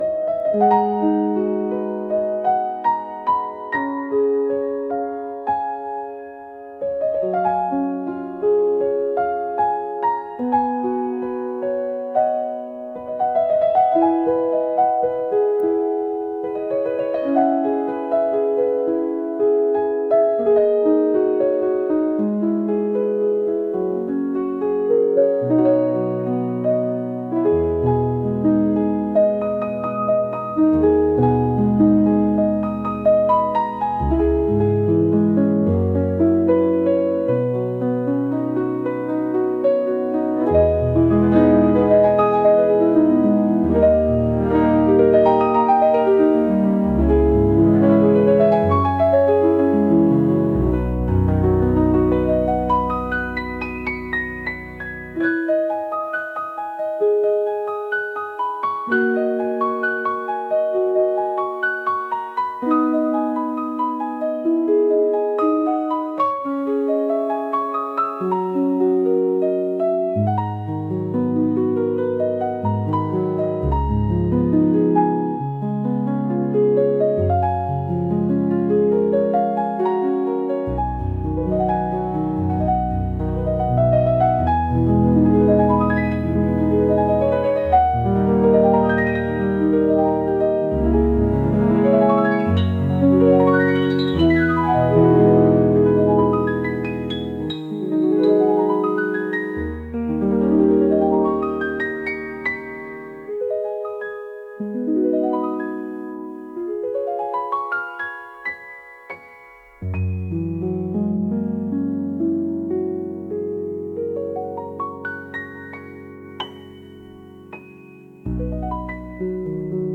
頑張って花が咲いたようなピアノ曲です。